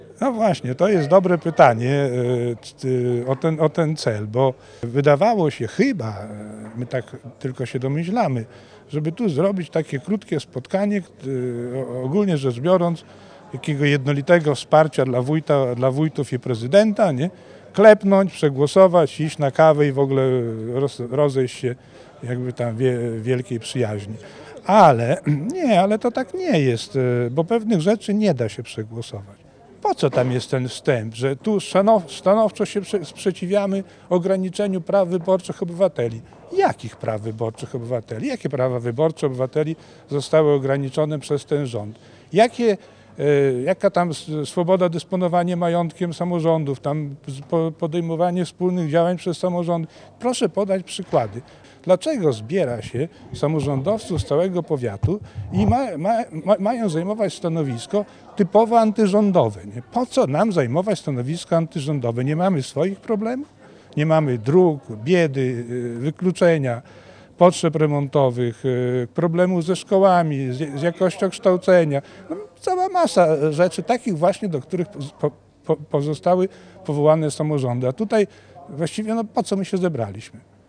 W podobnym tonie wypowiada się przeciwny dokumentowi Ryszard Skawiński, radny powiatowy z Prawa i Sprawiedliwości.